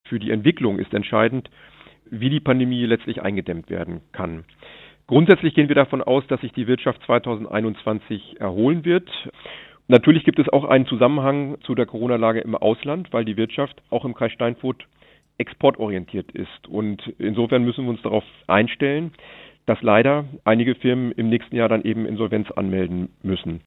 Landrat Martin Sommer